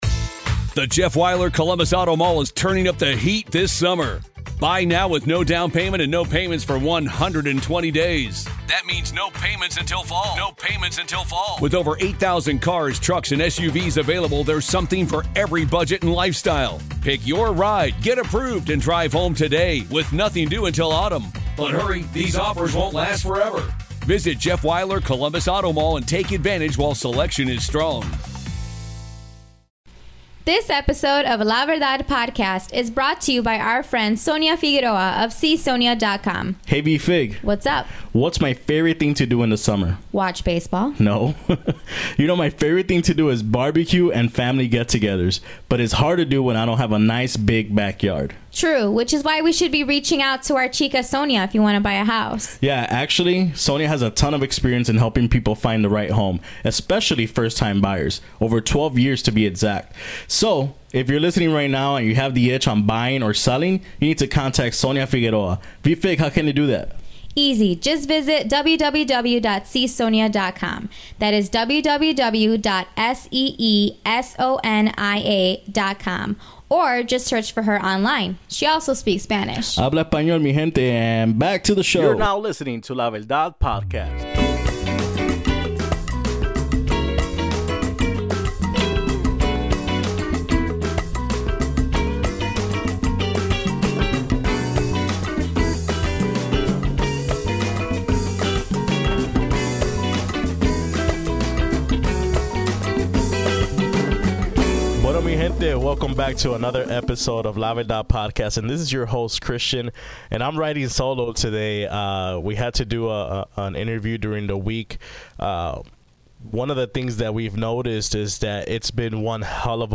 Programming, Percussion, Piano, Bass